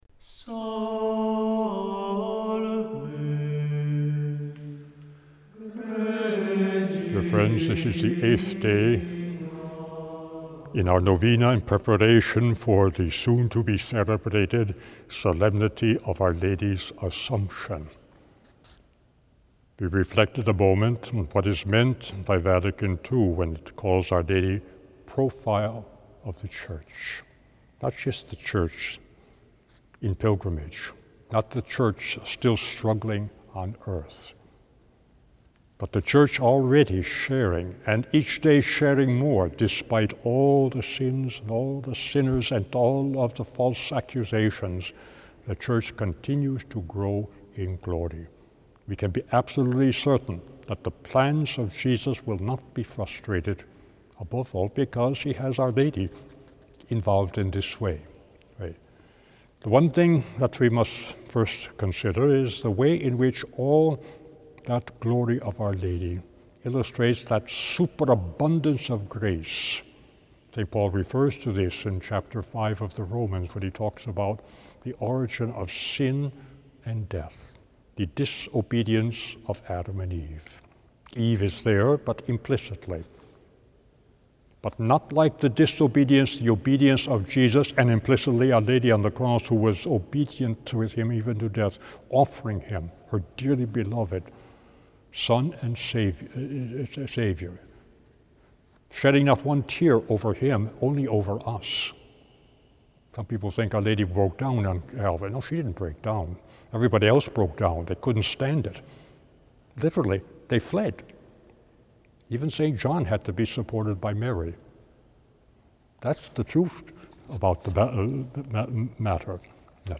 Each day, from the 6th up to the 14th, he gives a short reflection on Our Lady followed by a prayer.